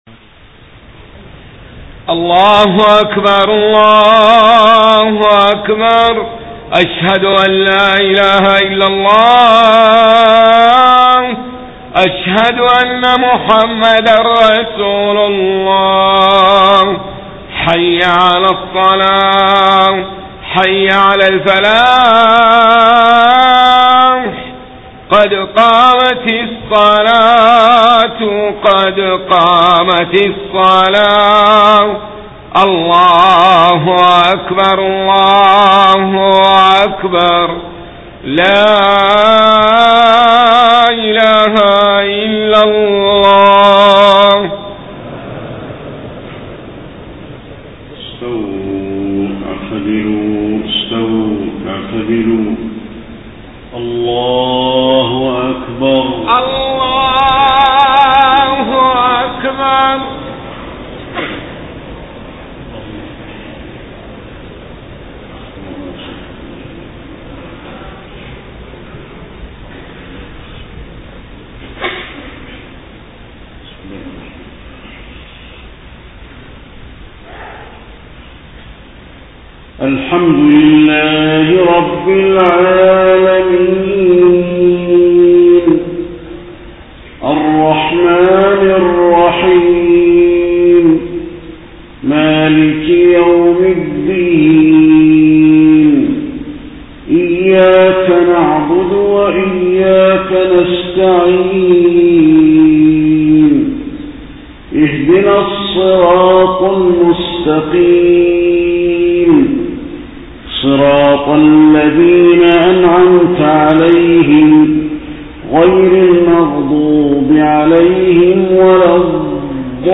صلاة العشاء 4 ربيع الأول 1431هـ من سورة إبراهيم 31-41 > 1431 🕌 > الفروض - تلاوات الحرمين